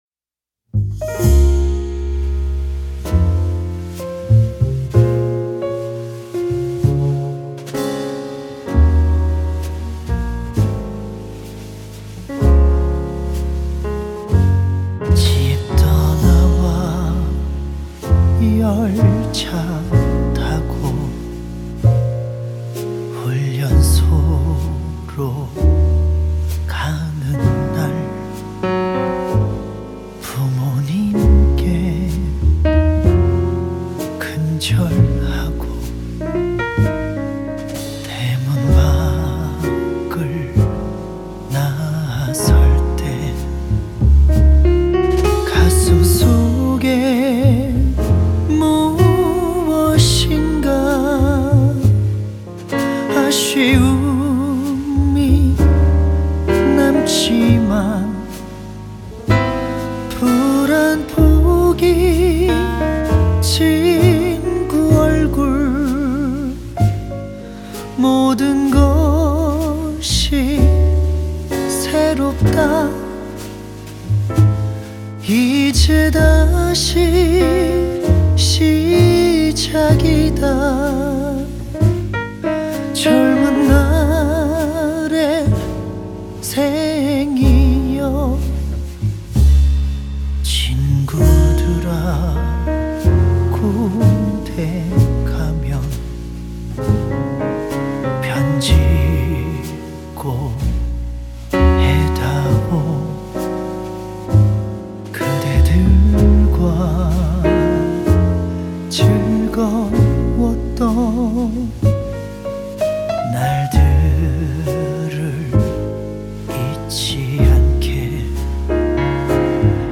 So clear, so controlled, so beautiful and so natural
so jazzy and sweet.